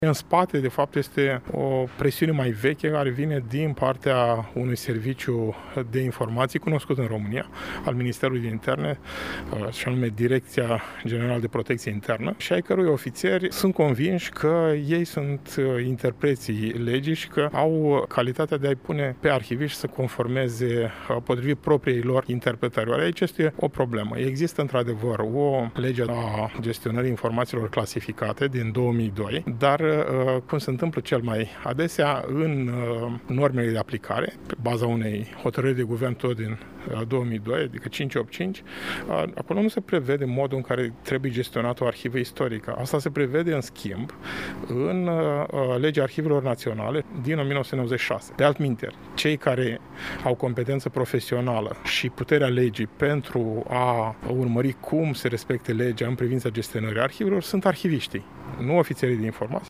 Institutul de Istorie „A.D. Xenopol” din Iași a găzduit astăzi dezbaterea intitulată „Documentele Secretului: Memoria istorică, libertatea de cercetare și politicile cu privire la arhivele publice în România.”